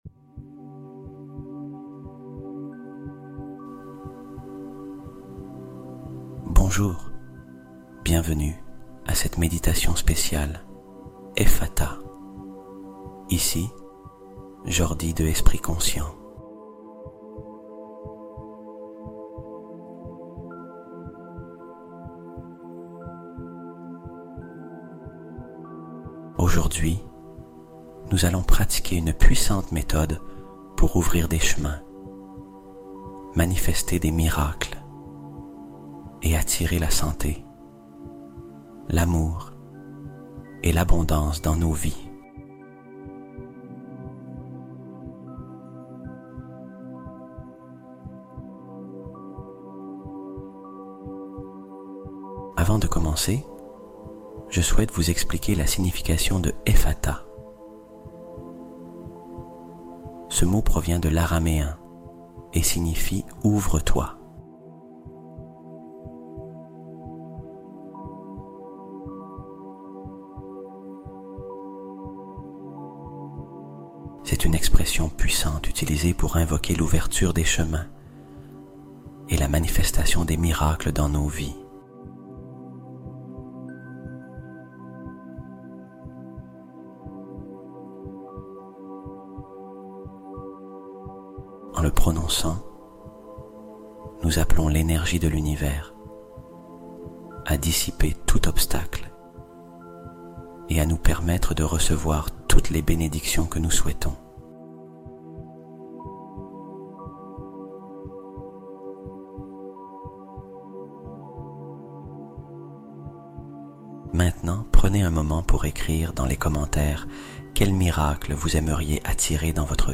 Fréquence Divine 432Hz : Harmonisation globale et paix intérieure